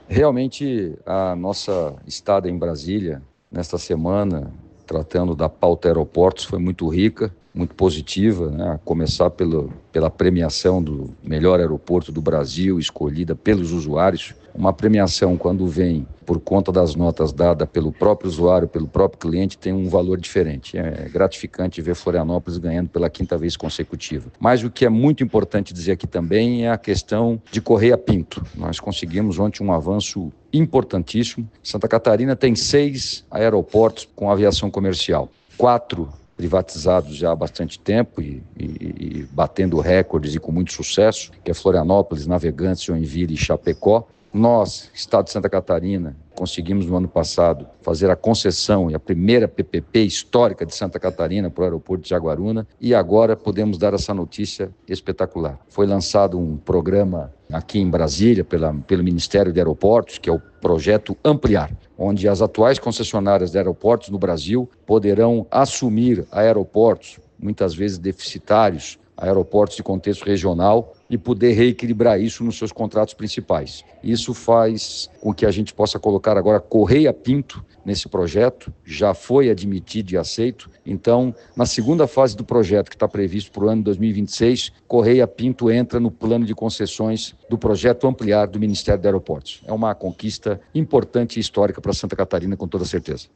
Com a inclusão no Programa AmpliAR, a ideia é atrair investimentos privados para modernizar e ampliar aeroportos regionais, como ressalta o secretário de Estado de Portos, Aeroportos e Ferrovias, Beto Martins: